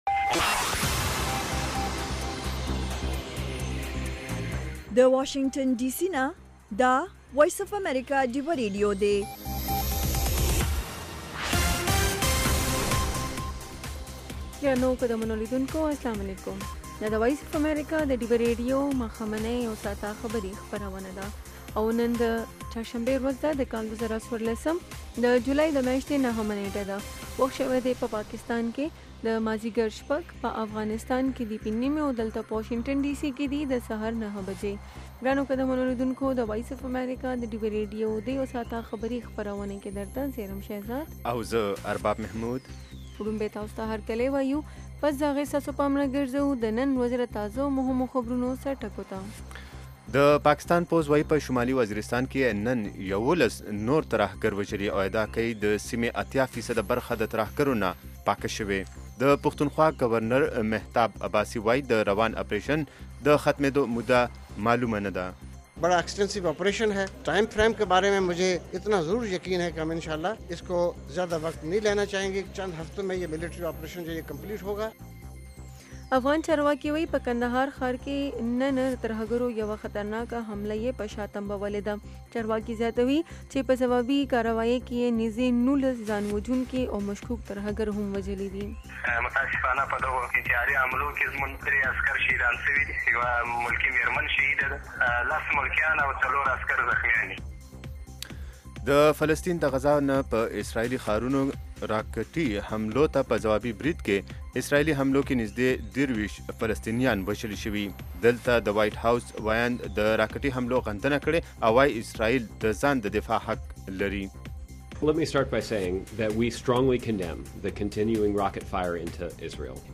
خبرونه - 1300